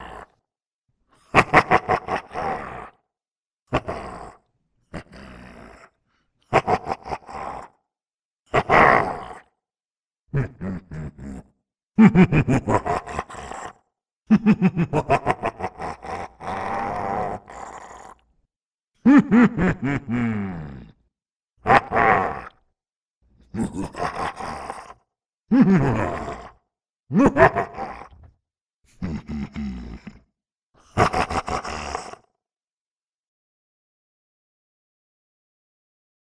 Evil Laughs Demonic Echos
demon demonic evil laugh laughing laughter sound effect free sound royalty free Funny